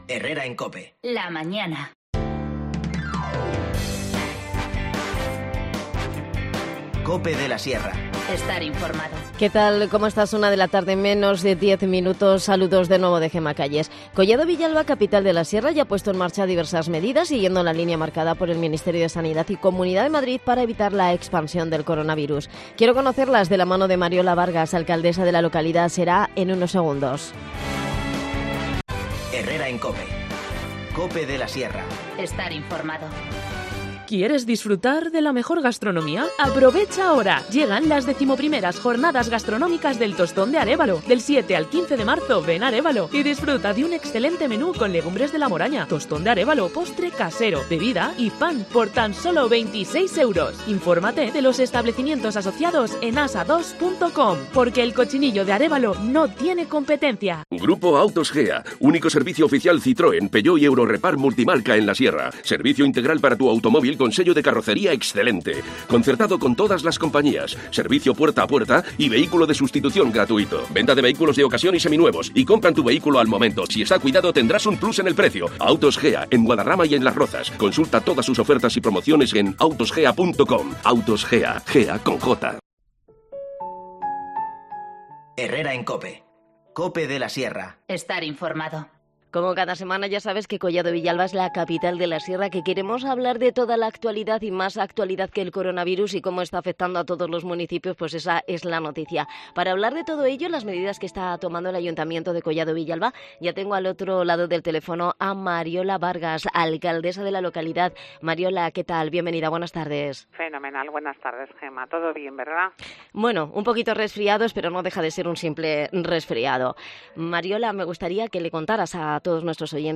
AUDIO: Mariola Vargas, alcaldesa de Collado Villalba, nos cuenta todas las medidas preventivas que ha tomado el ayuntamiento frente al Covid-19